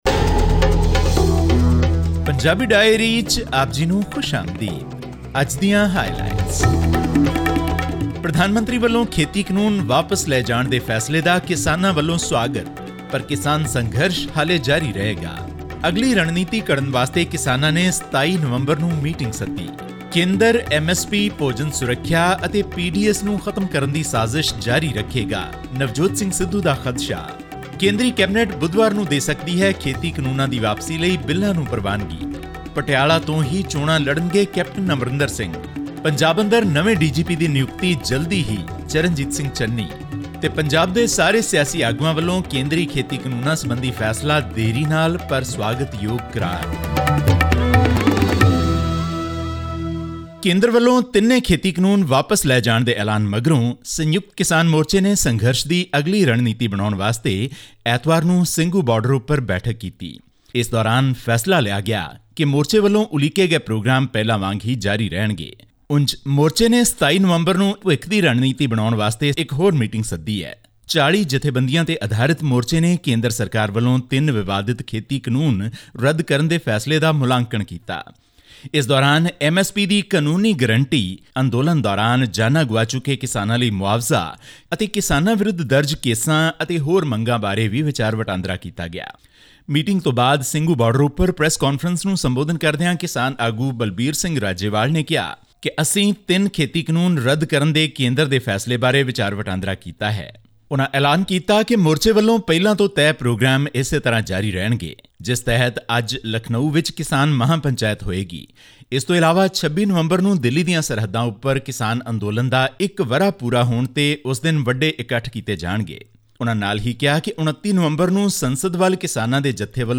Despite the government's decision to repeal the three controversial farm laws, the farmers' bodies on 20 November declared that will continue their protests until all their pending demands are met. This and more in our weekly news update from Punjab.